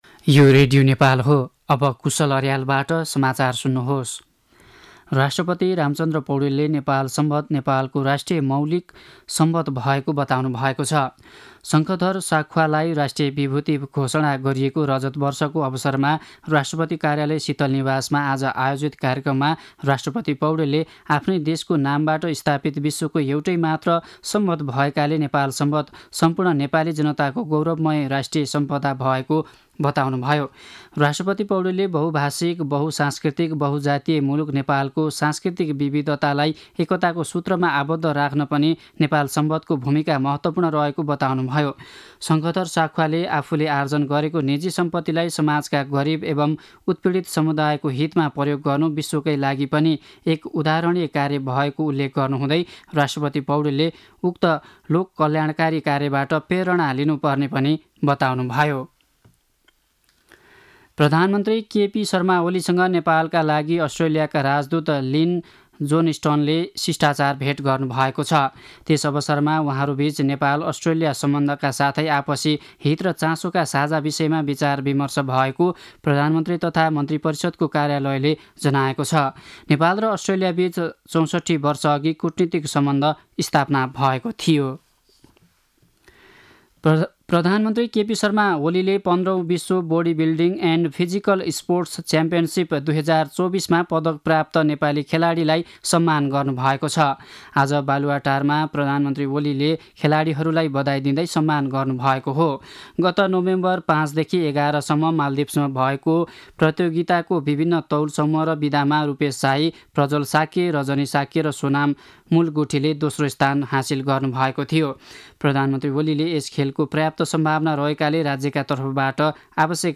An online outlet of Nepal's national radio broadcaster
साँझ ५ बजेको नेपाली समाचार : ५ मंसिर , २०८१